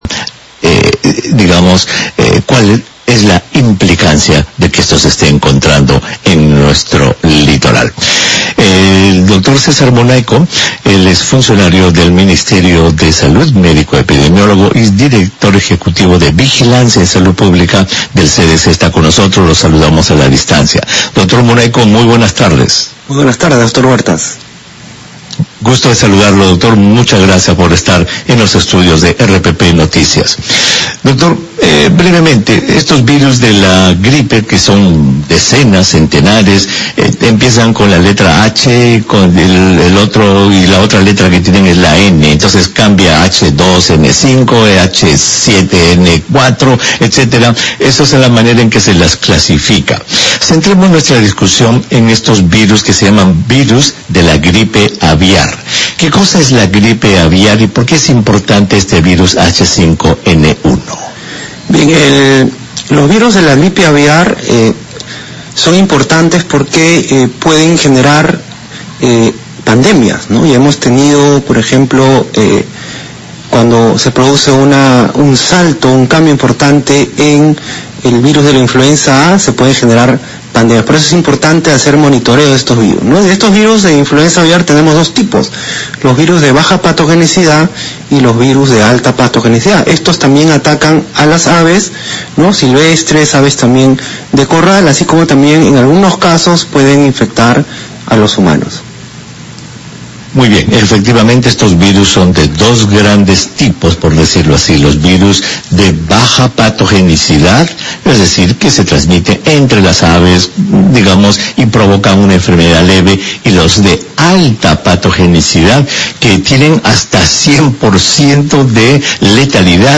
Entrevista a César Munayco director ejecutivo de Vigilancia en Salud Pública del CDC